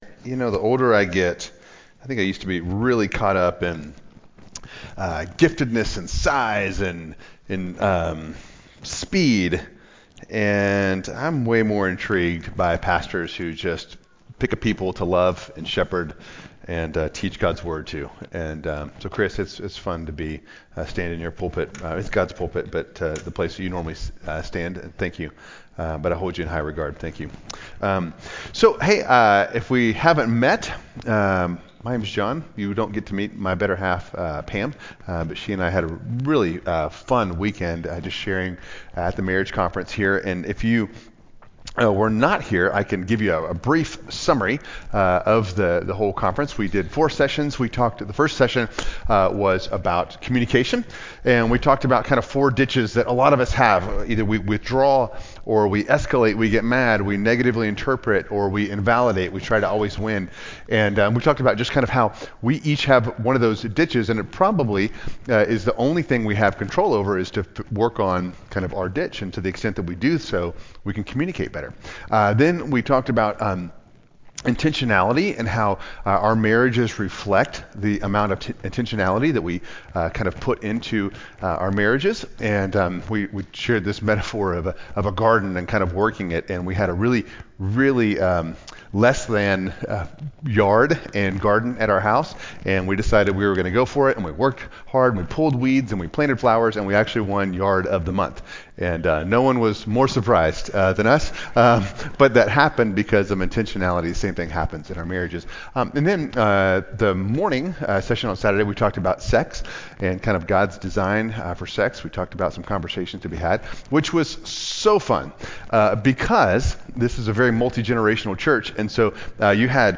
From Series: "Special Sermons"